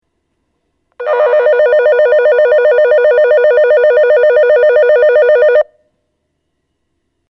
電子電鈴（低音）
発車ベル
4秒鳴動です。鳴動時間は決まっています。